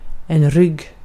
Uttal
Synonymer rigg kam ås Uttal : IPA: [rʏg] Okänd accent: IPA: [rʏɡ] Ordet hittades på dessa språk: svenska Översättning 1. sırt Artikel: en .